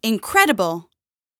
8 bits Elements
Voices Expressions Demo
Incredible_1.wav